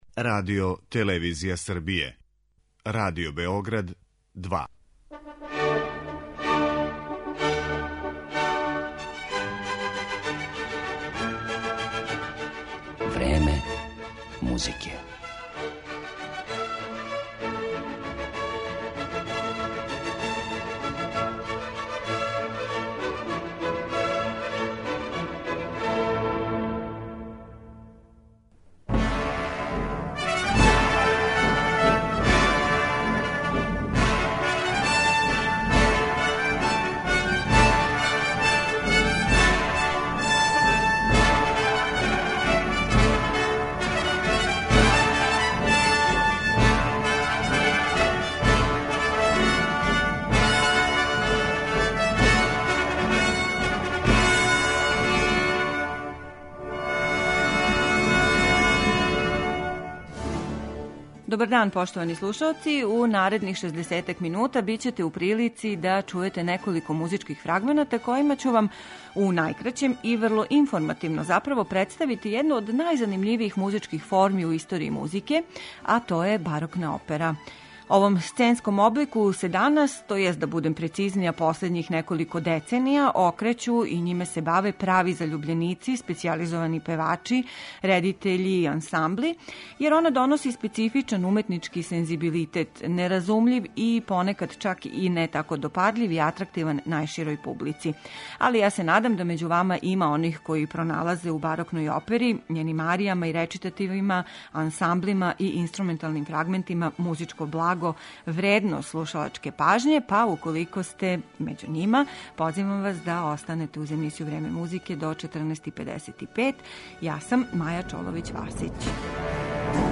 Имаћете прилику да чујете фрагменте из неких од најпознатијих барокних опера чији су аутори, између осталих, Монтеверди, Персл, Лили, Кавали и Хендл.